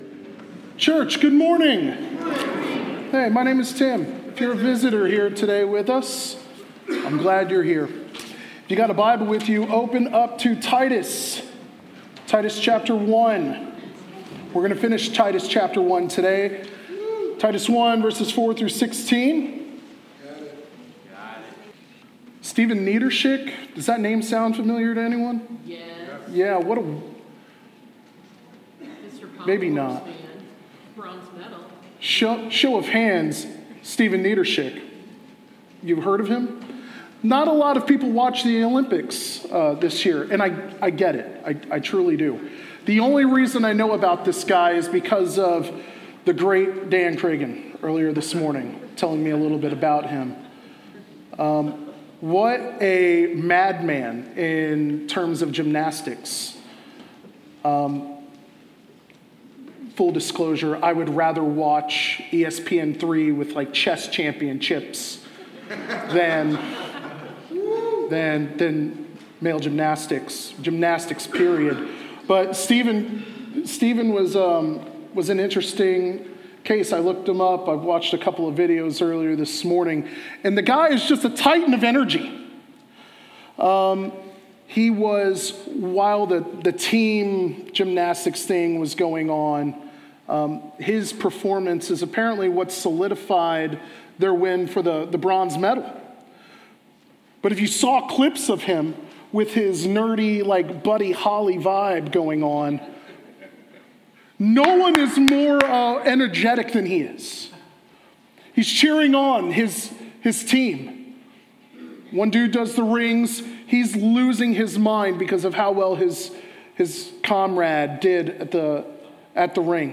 Sermons | Hazelwood Baptist Church